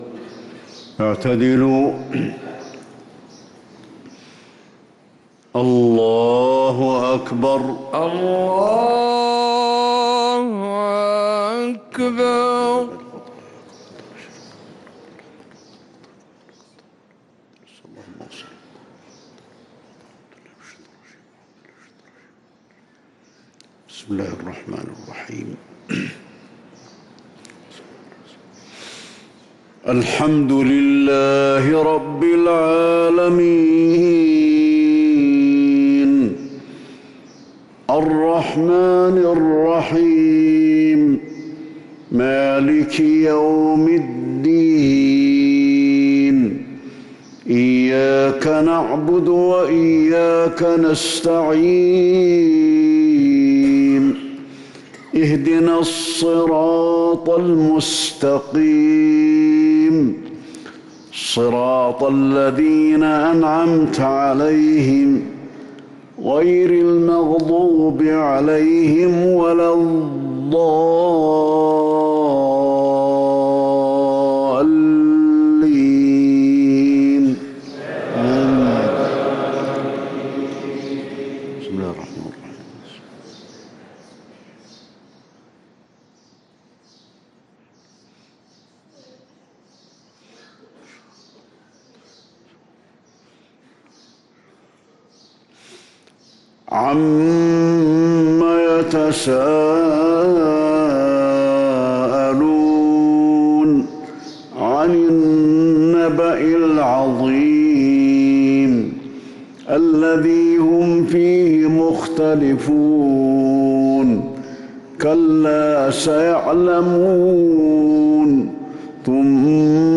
صلاة الفجر للقارئ علي الحذيفي 27 ربيع الأول 1445 هـ
تِلَاوَات الْحَرَمَيْن .